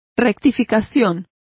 Complete with pronunciation of the translation of corrections.